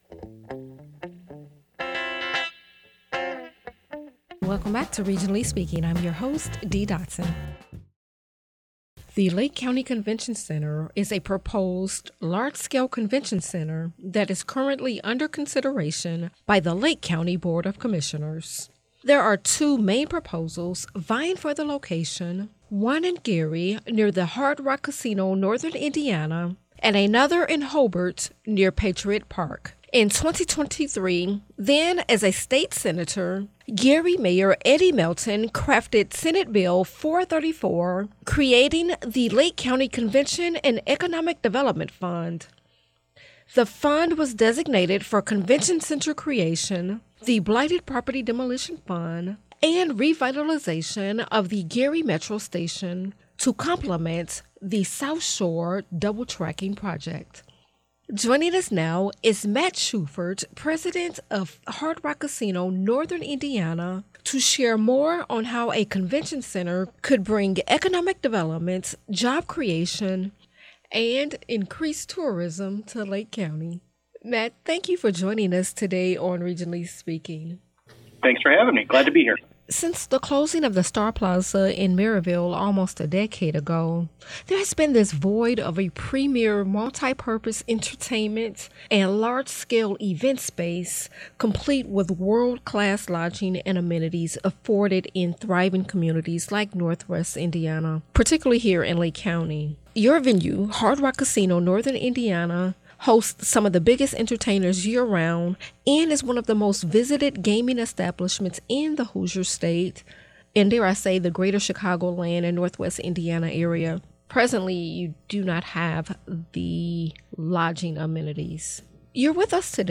Addressing the most important local issues facing the Region during a daily hour of stimulating conversation with local news-makers.